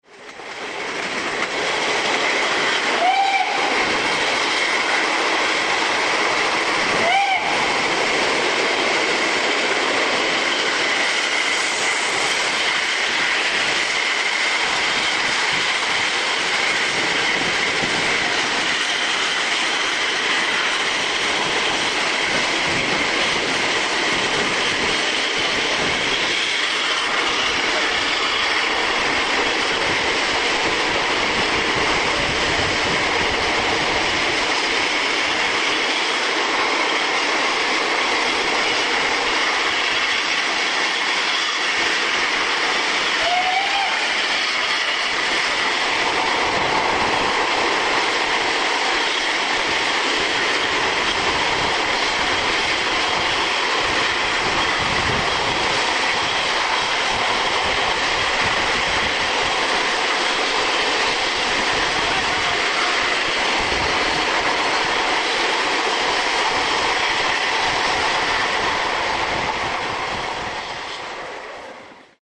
These audio files, taken from video recorded on 'Le Mistral' will give some impression.
Accelerating up to 100kph from Grenoble (847KB)
grenoble.mp3